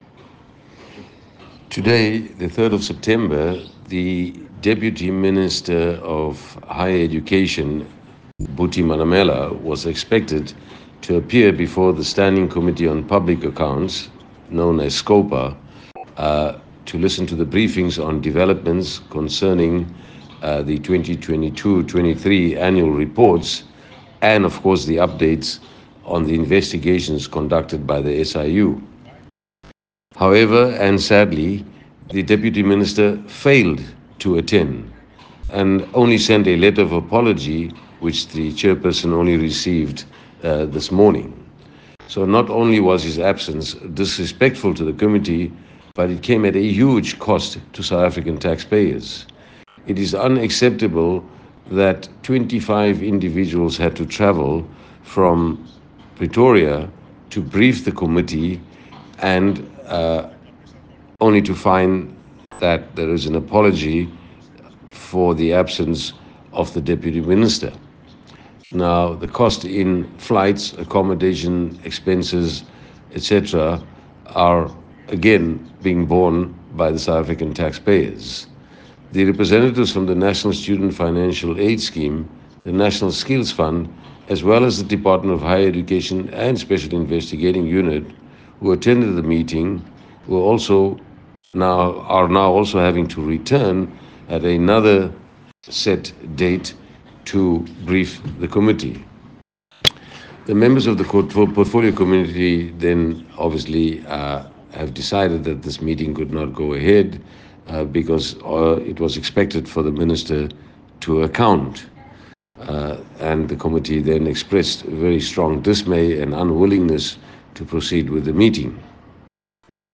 soundbite by Farhat Essack MP